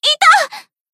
BA_V_Pina_Battle_Damage_2.ogg